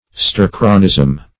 Search Result for " stercoranism" : The Collaborative International Dictionary of English v.0.48: Stercoranism \Ster"co*ra*nism\, n. (Eccl.